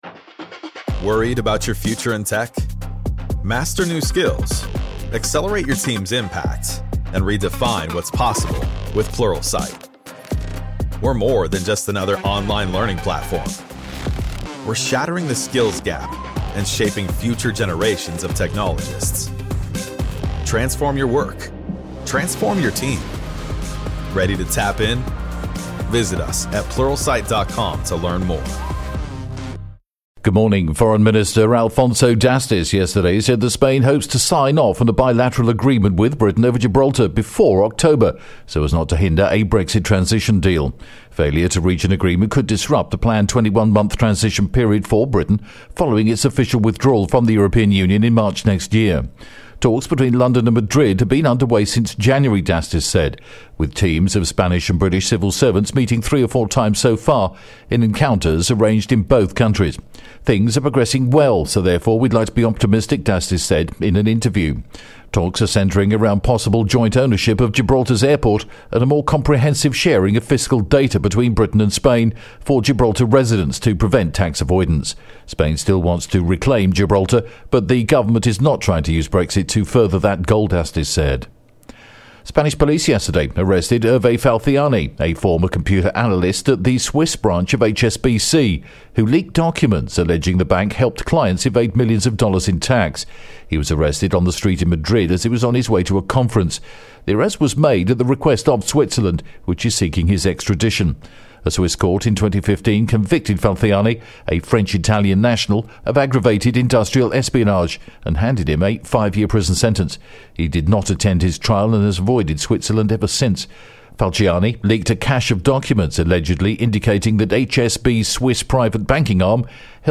The latest Spanish News Headlines in English: April 5th am